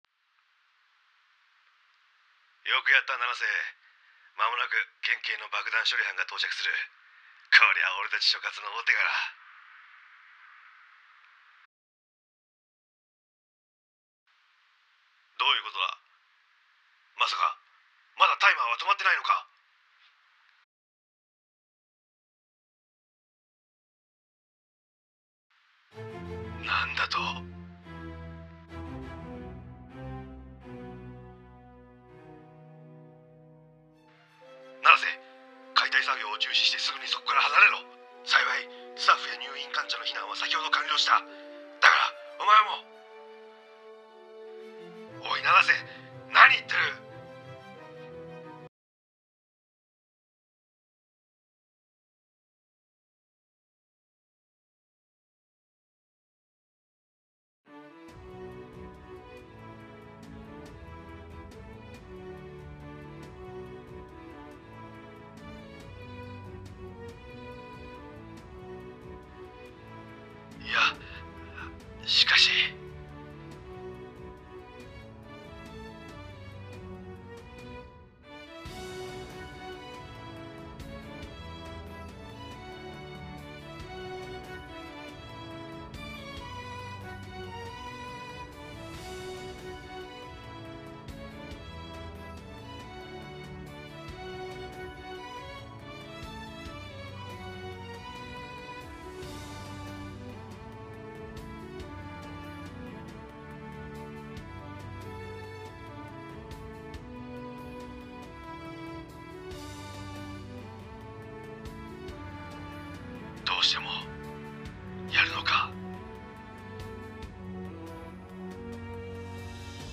１人劇